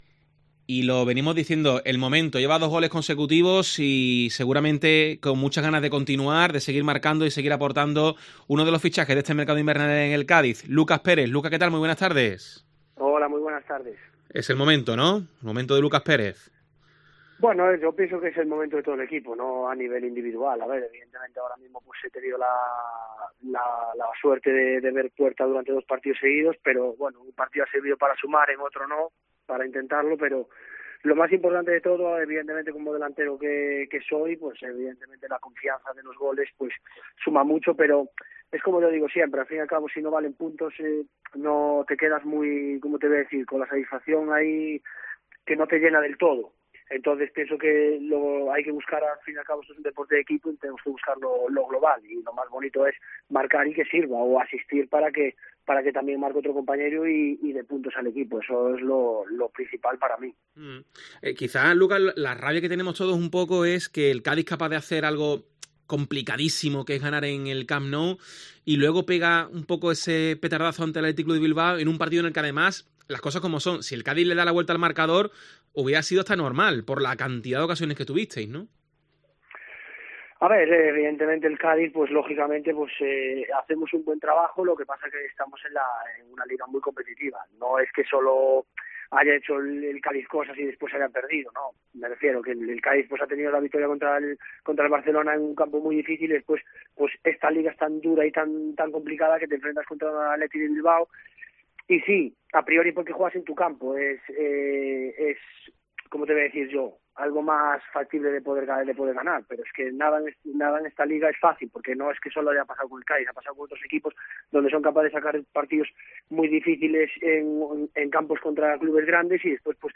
El jugador ha pasado por los micrófonos de COPE Cádiz .